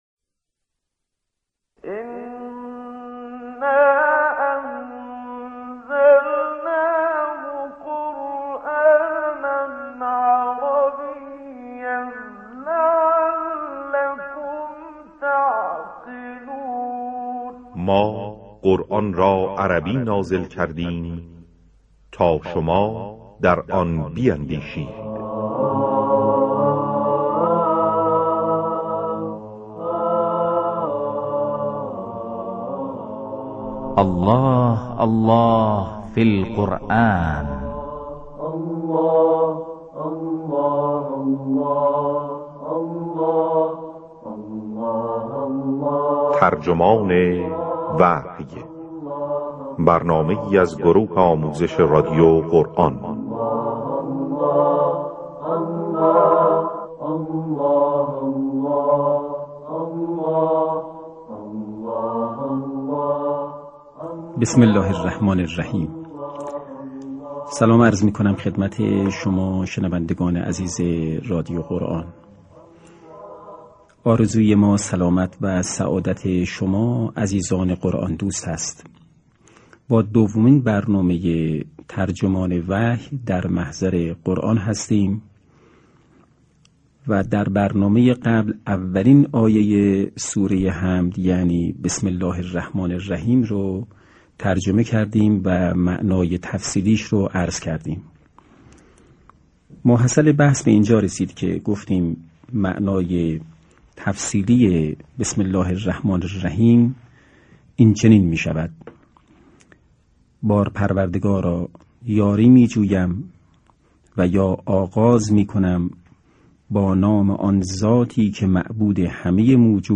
این مجموعه (ترجمان وحی)، طی سال‌های 1382 تا 1390 از رادیو قرآن، پخش گردید.